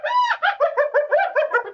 animalworld_hyena.ogg